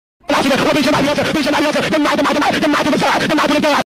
Goofy Ahh Earrape Noises - Bouton d'effet sonore